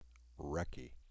This plays the word pronounced out loud.